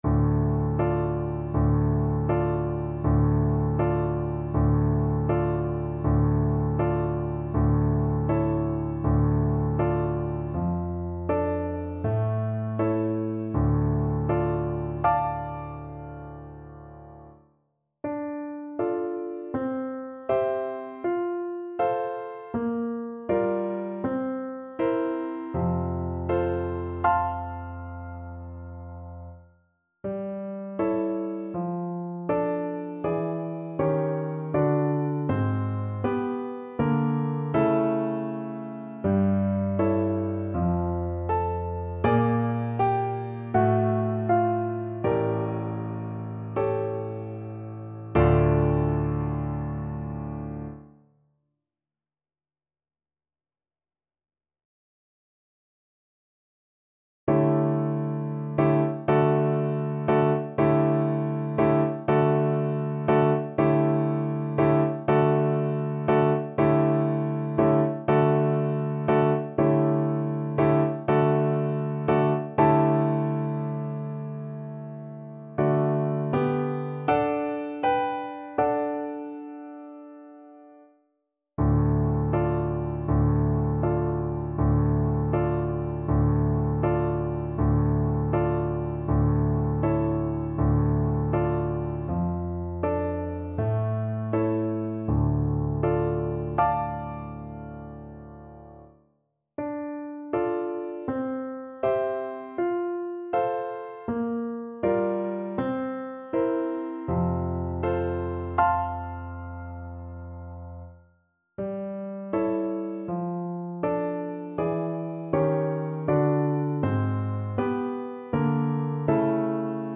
4/4 (View more 4/4 Music)
Un poco andante
Classical (View more Classical Flute Music)